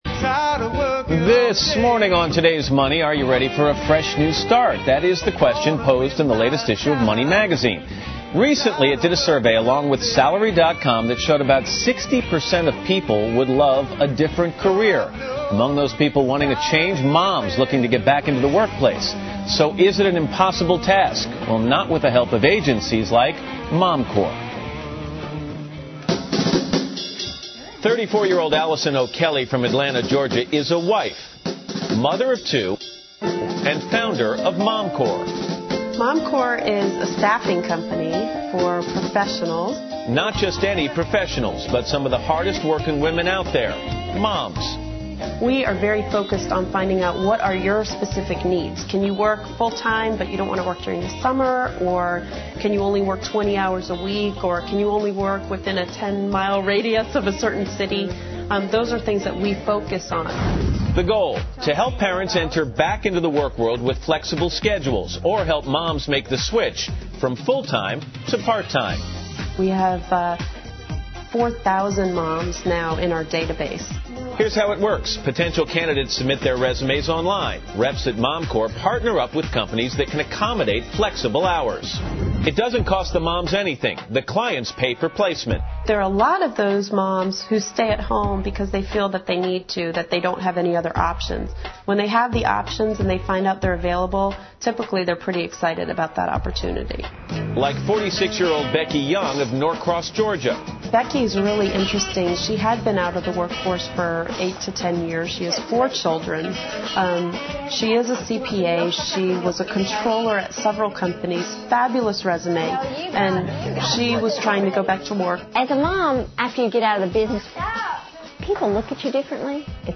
访谈录 Interview 2007-04-03&05, 你要换工作吗?